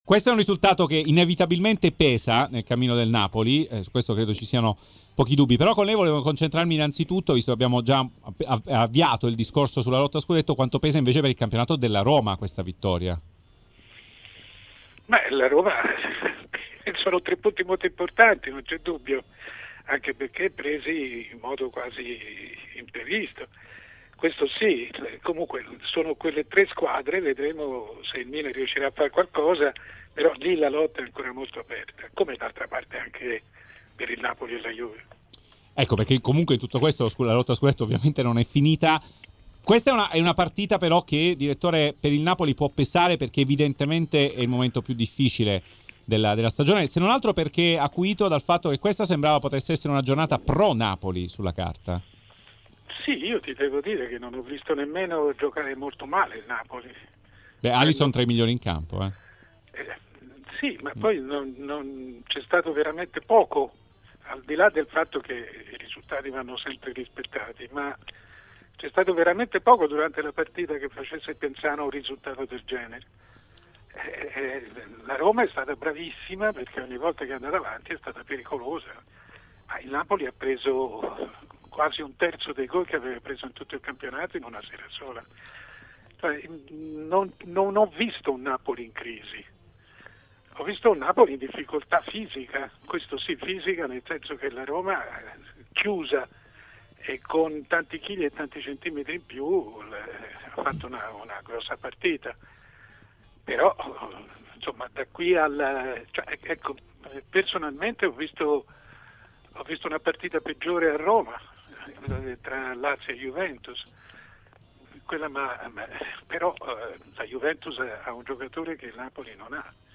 Mario Sconcerti, storica penna del giornalismo sportivo, a RMC Sport Live Show commenta il sabato della Serie A